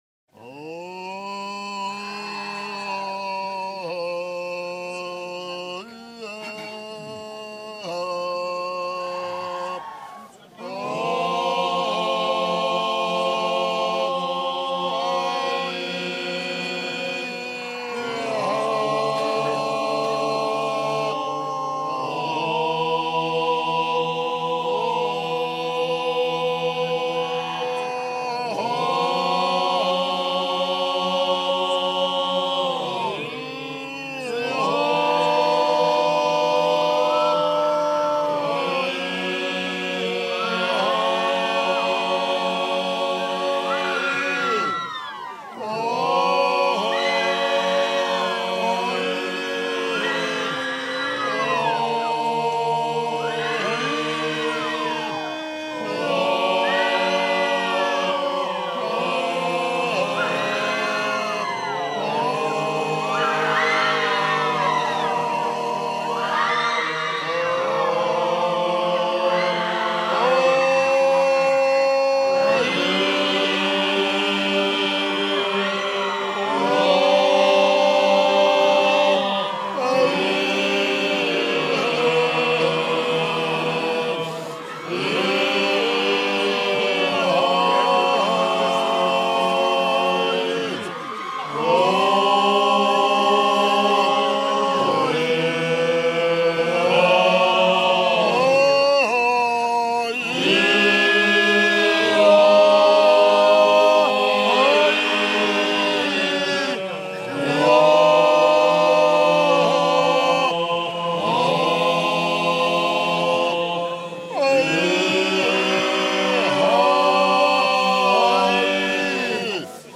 Svaneti_-_Zari.mp3